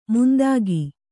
♪ mundāgi